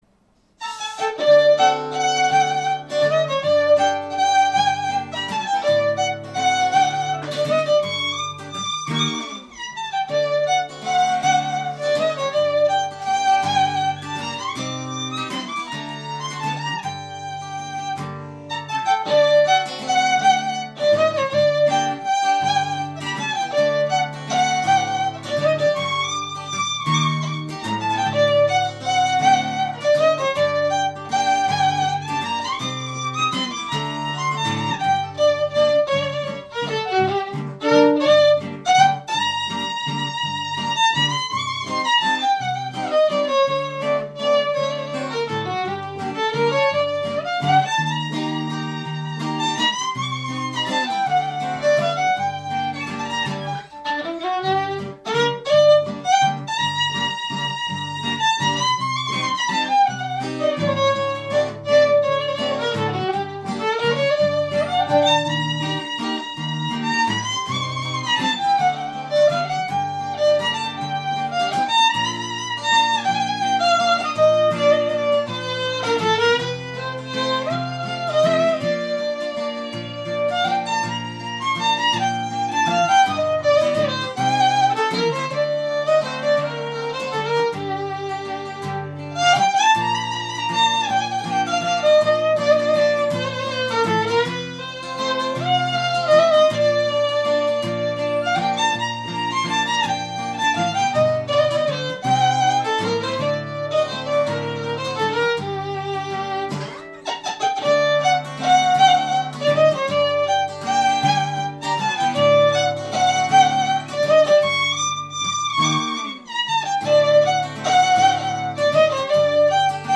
Klezmer/Gypsy Violin